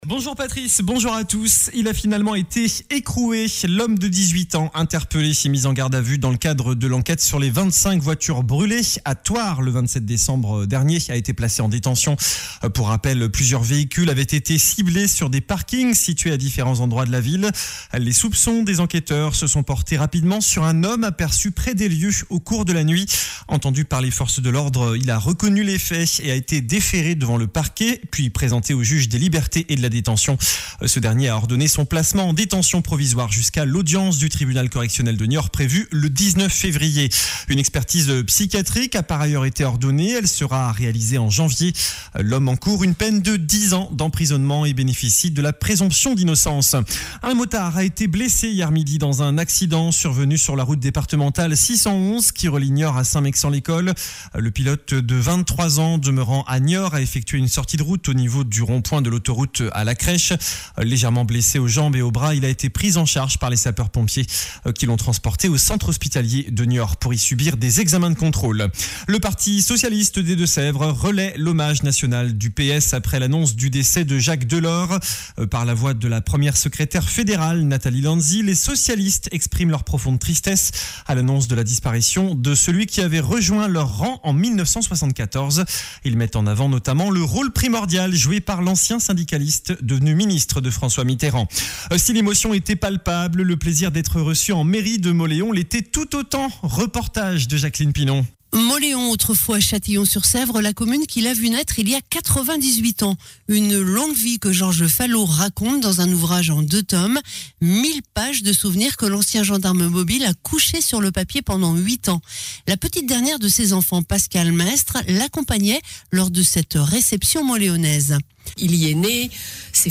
JOURNAL DU SAMEDI 30 DECEMBRE